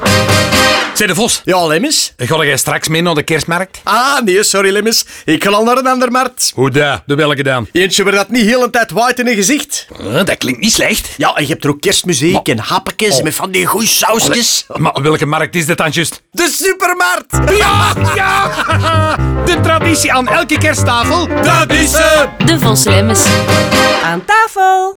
In de nieuwe radiospots komen ze smakelijk aan bod.
DevosLemmens_NL25s_Kerstmarkt_Radio.wav